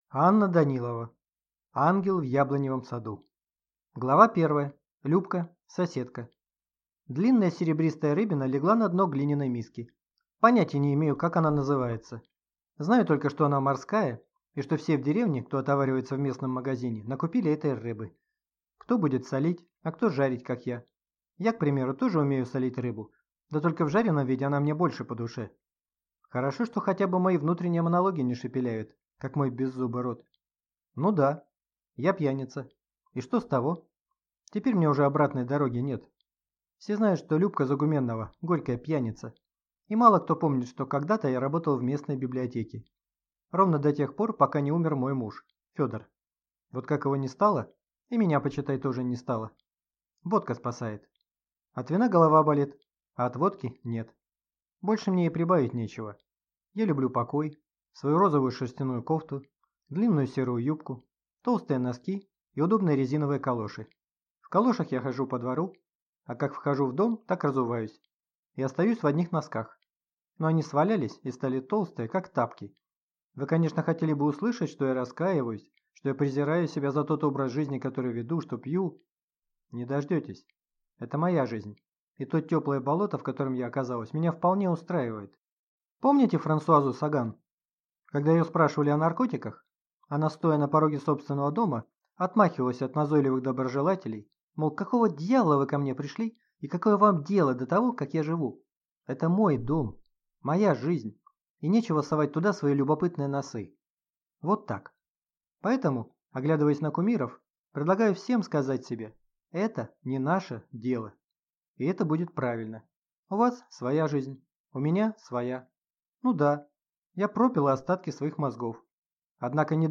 Аудиокнига Ангел в яблоневом саду | Библиотека аудиокниг